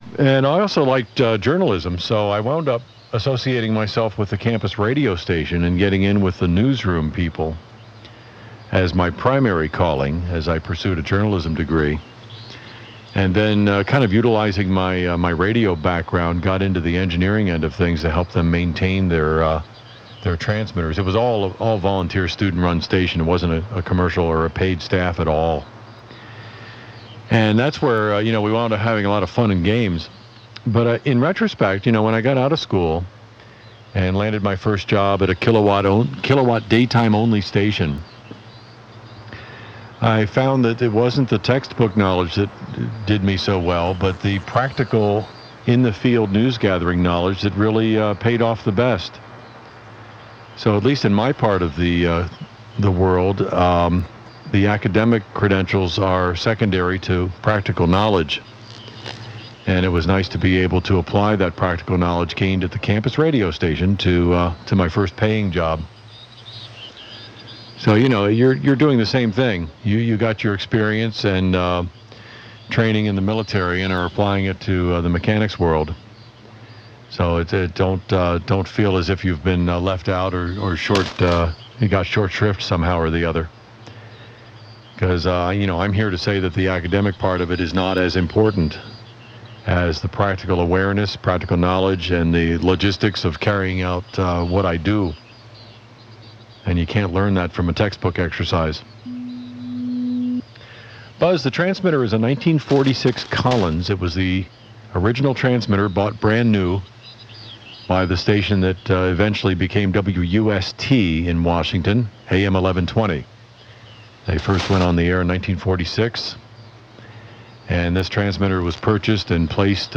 The 75 Meter AM Audio Files